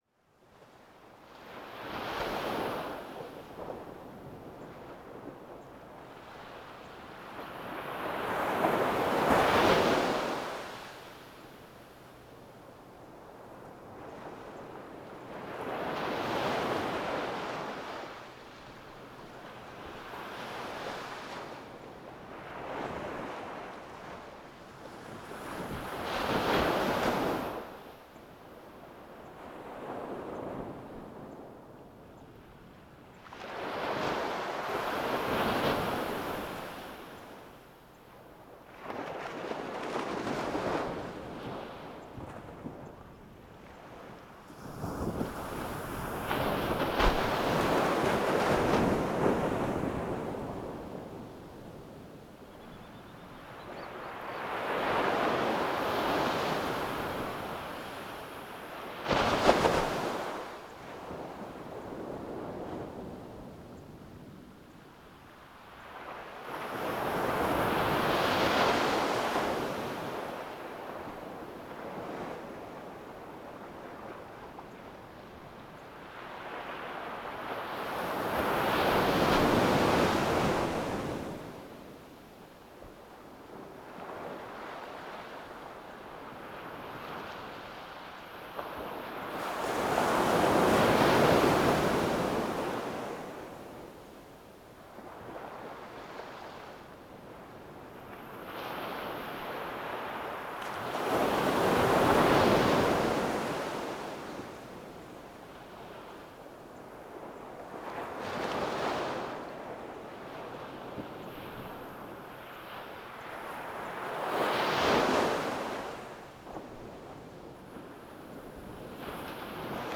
• Tascam DR-05X is a handheld recorder that uses a 3.5mm TRS connector for an external microphone.
This Saturday morning, I woke up really (really) early and went to give a shot at one of the nearby beaches.
So, I didn't correctly select the input of the right mic in a few recordings, and some other stuff didn't sound too well, but at least some waves got more or less alright - although, a lot of birds ended up in the recording too.
There was one recording where I got the level config somewhat better than the others, I loaded up that one on Audacity, added a small fade in and fade out at start and end, and exported it to an Ogg file that I think is game (and AGS) ready.
waves.ogg